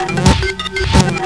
Play MAC QuadraAV Crash Normal + Reversed - SoundBoardGuy
Play, download and share MAC QuadraAV crash normal + reversed original sound button!!!!